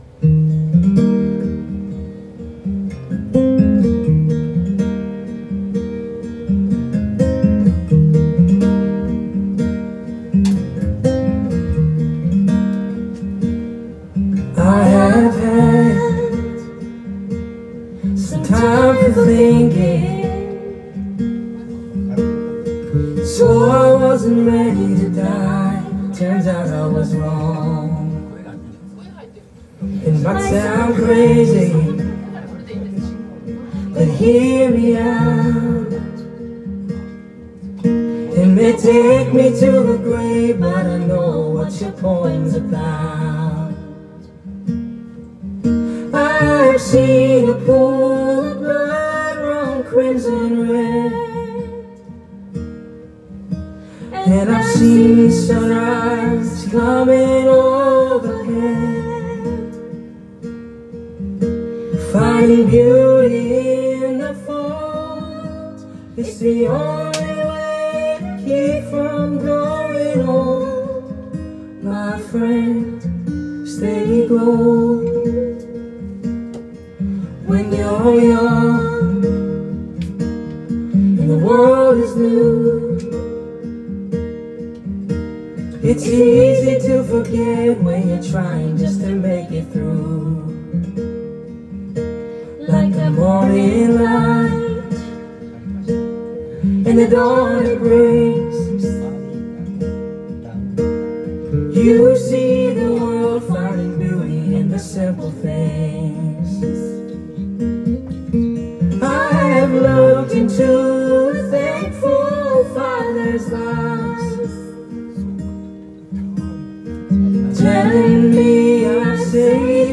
Duet & Chorus Night Vol. 19 TURN TABLE